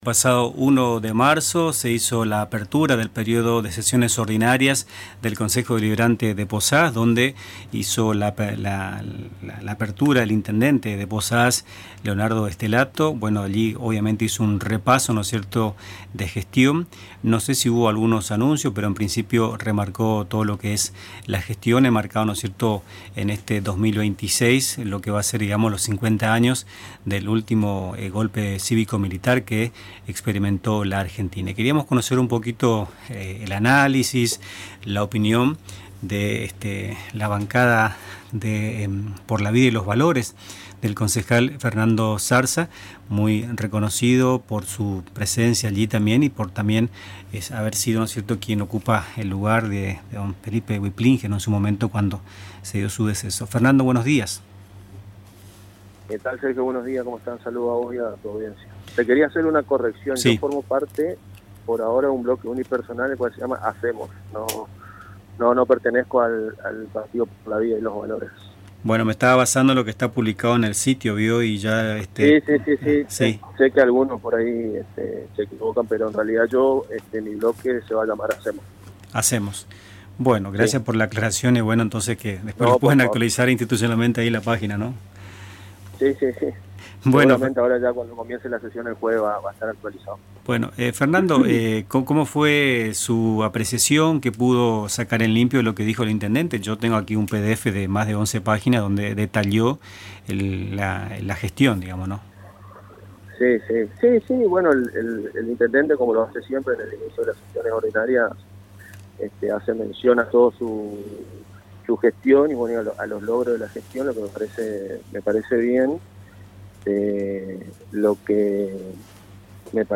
Tras el discurso oficial, y en diálogo con Nuestras Mañanas, el concejal Fernando Zarza manifestó una postura crítica al señalar omisiones en temas centrales vinculados a los servicios públicos. Cuestionó a Servicios de Aguas de Misiones S.A. (SAMSA) por los reiterados cortes y las facturas elevadas, apuntó contra el Ente Provincial Regulador de Aguas y Cloacas (EPRAC) por la falta de controles y reclamó mayor transparencia en los subsidios al transporte, además de advertir sobre problemas de seguridad y anticipar posibles acciones legislativas.